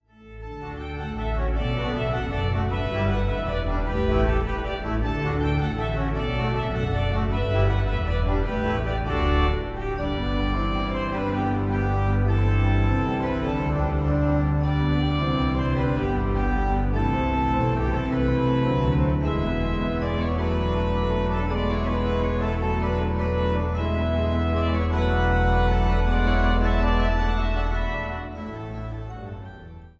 Schuke-Orgel der Kirche St. Divi Blasii Mühlhausen